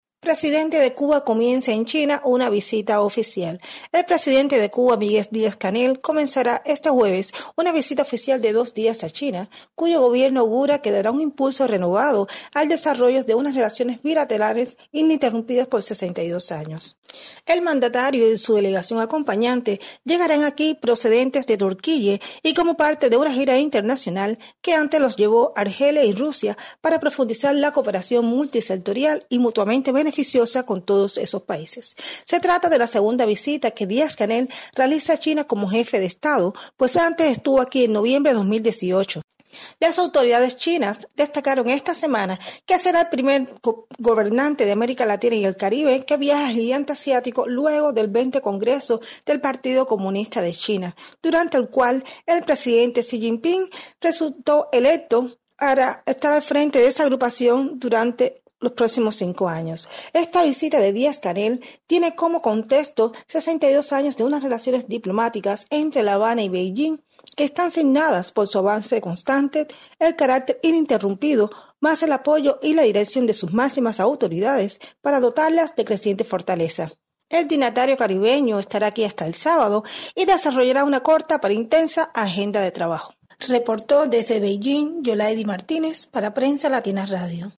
desde Beijing